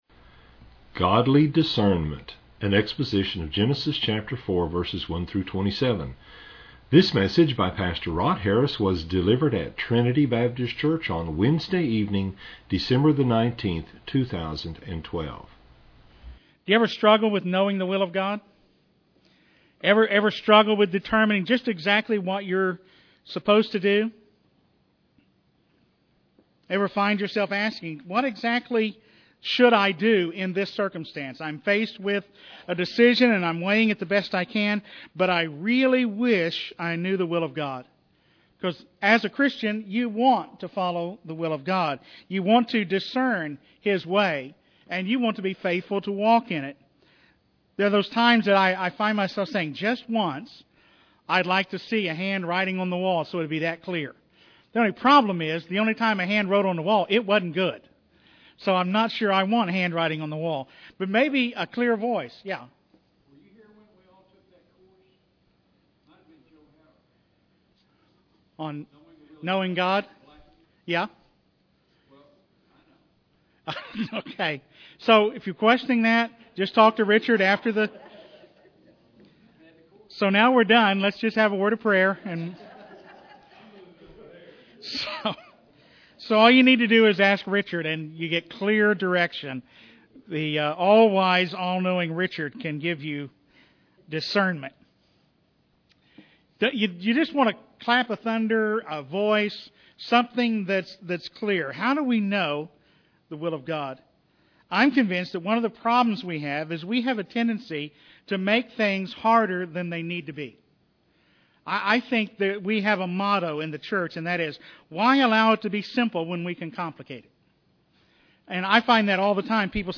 delivered at Trinity Baptist Church on Wednesday evening, December 19, 2012.